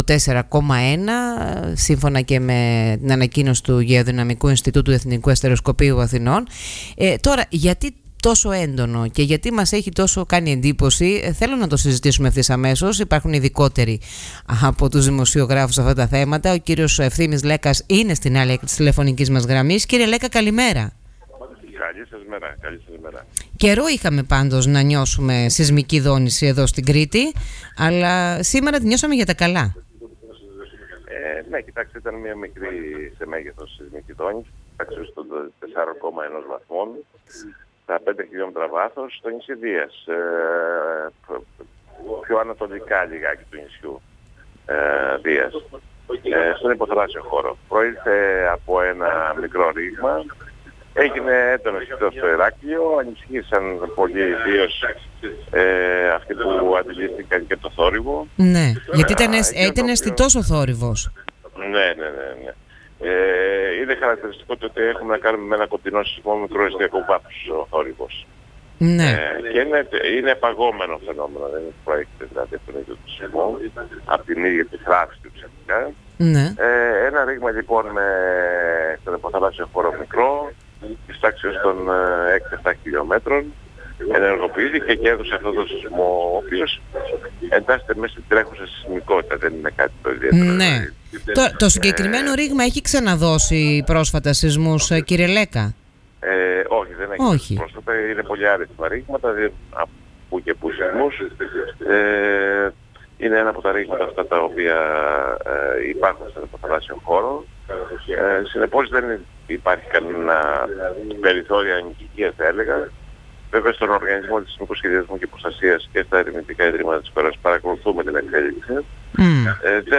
O γνωστός σεισμολόγος μίλησε στον Politica 89,8 τονίζοντας ότι ο σεισμός στο Ηράκλειο προκλήθηκε από την ενεργοποίηση του ρήγματος της Ντίας ενώ τόνισε ότι δεν πρέπει να υπάρχει ανησυχία στον κόσμο για μετασεισμούς.